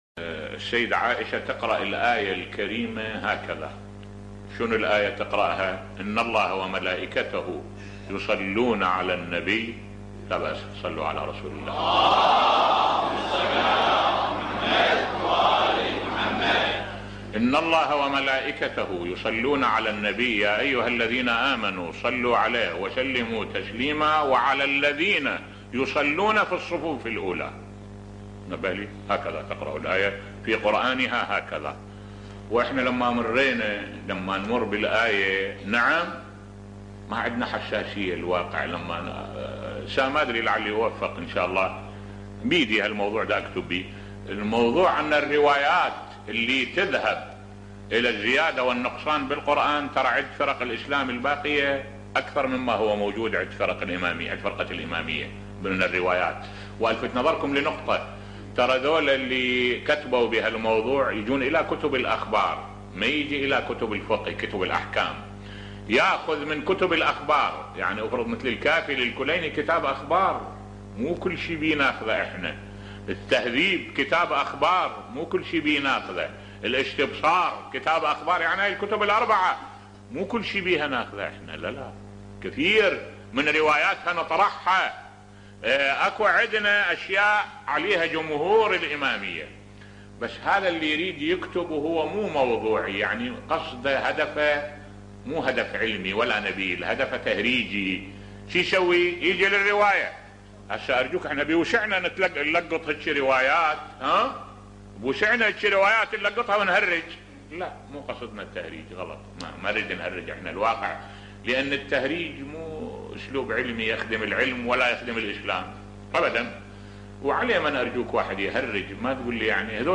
ملف صوتی روايات الزيادة و النقصان في القران الكريم بصوت الشيخ الدكتور أحمد الوائلي